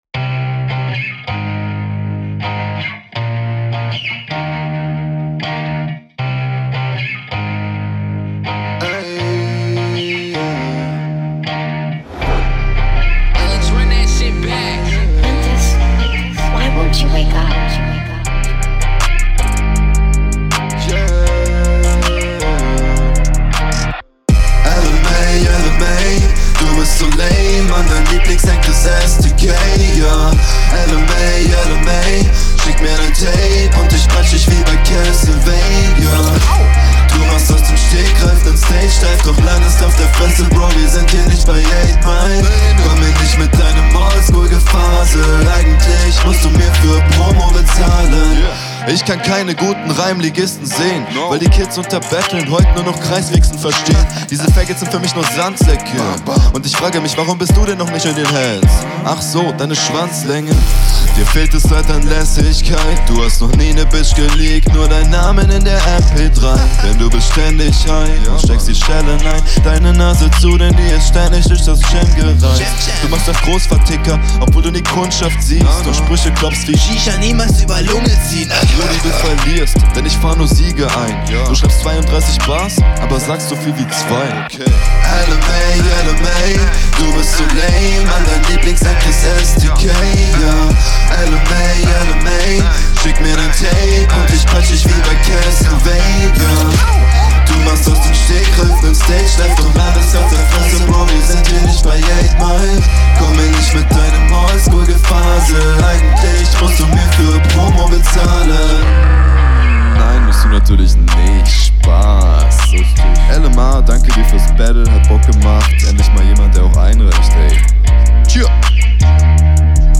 Flow: musikalisch. Hook geht gut ins Ohr.